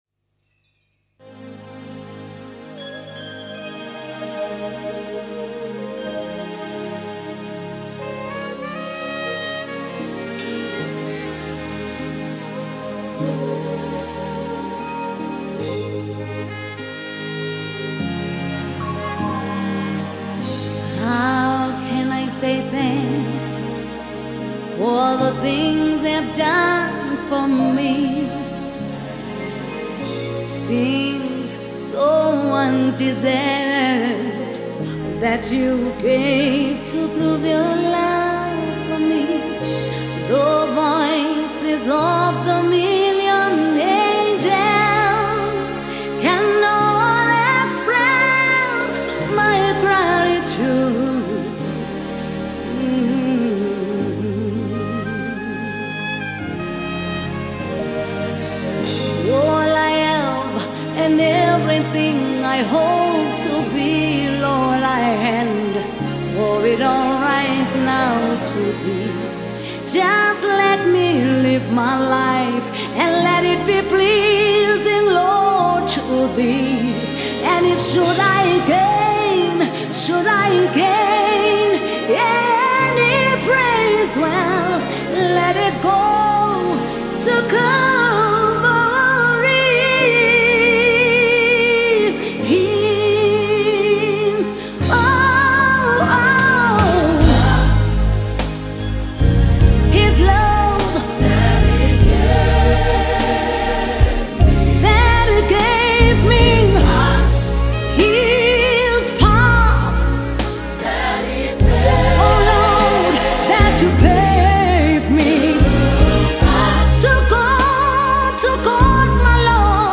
Gospel